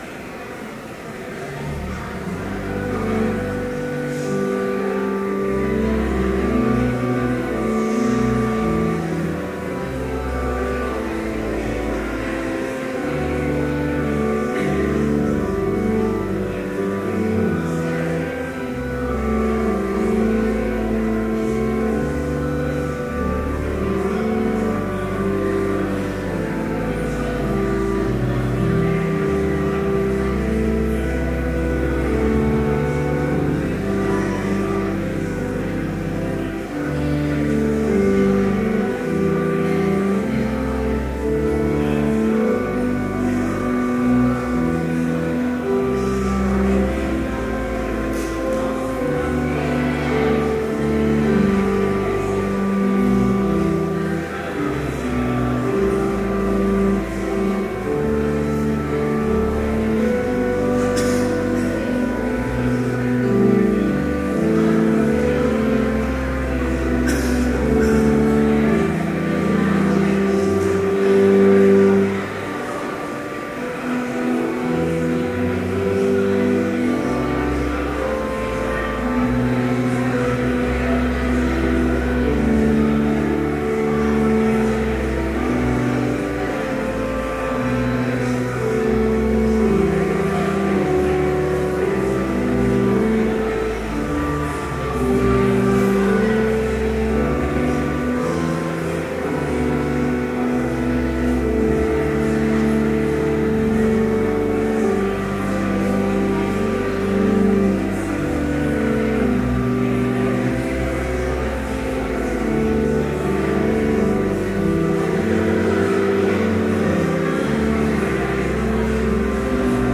Complete service audio for Chapel - September 26, 2013
Order of Service Prelude Hymn 283, Glory Be to Jesus Reading: I Peter 2:9-10 Homily Prayer Hymn 60, O Bless the Lord, My Soul Benediction Postlude